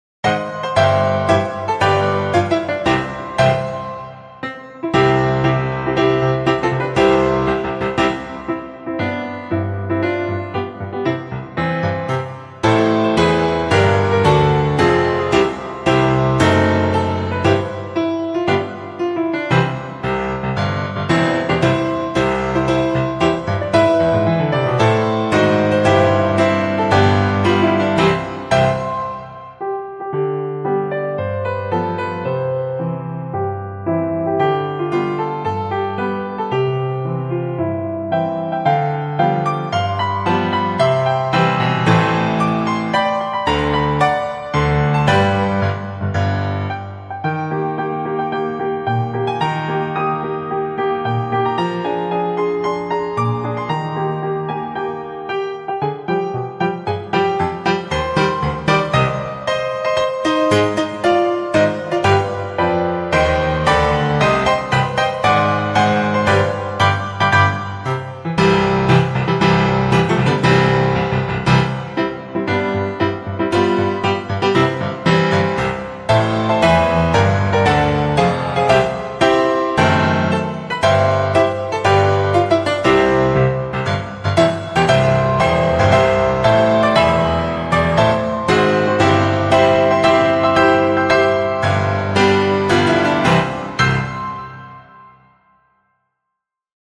На пианино